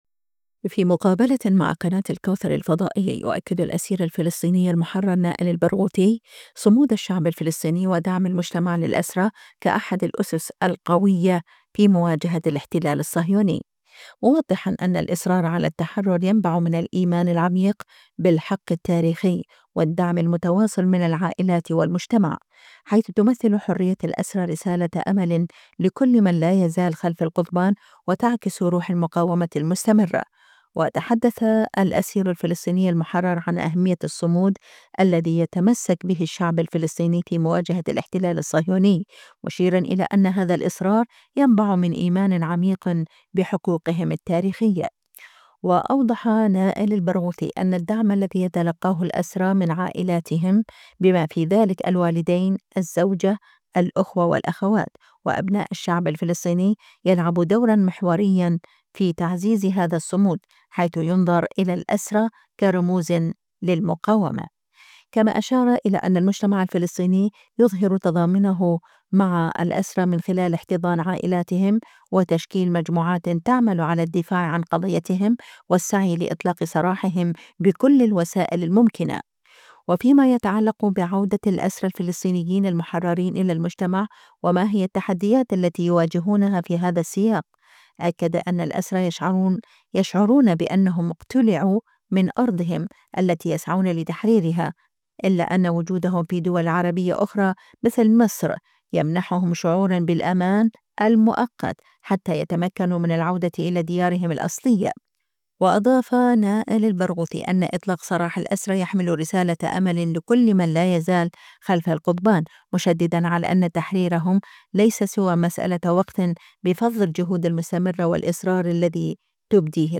خاص الكوثر_ مقابلات